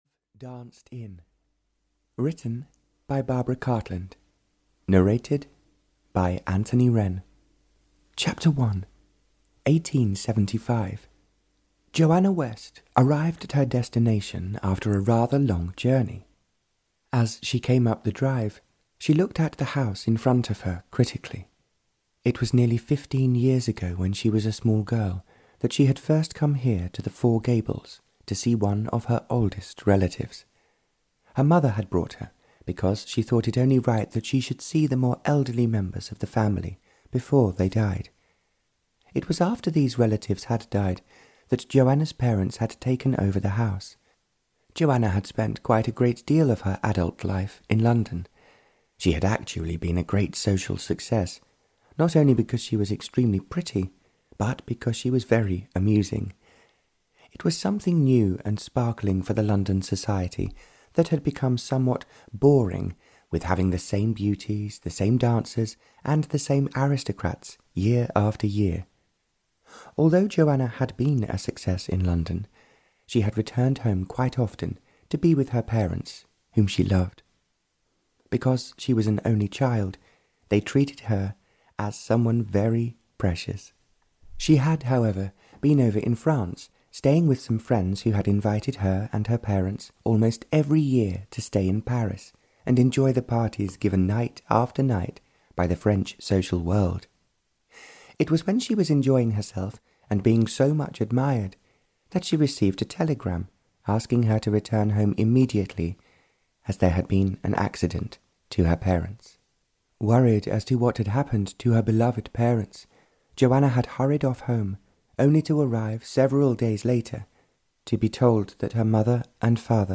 Audio knihaLove Danced In (Barbara Cartland's Pink Collection 159) (EN)
Ukázka z knihy